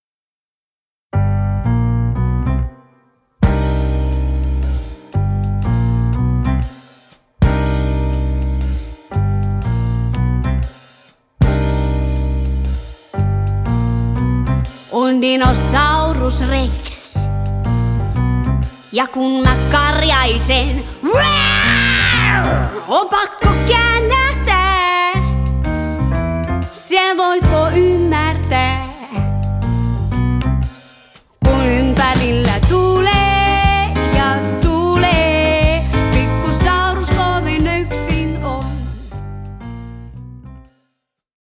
jazz blues